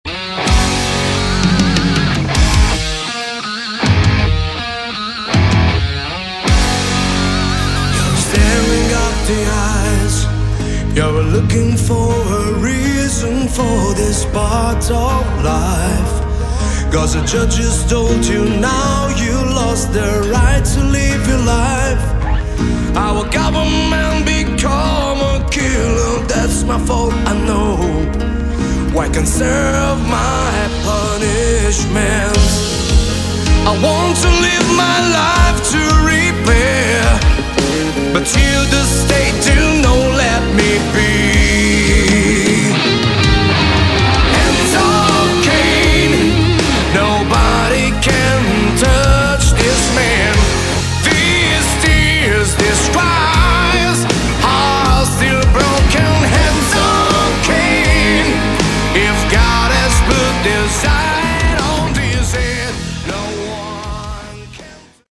Category: Prog Rock
piano, keyboards
guitars
drums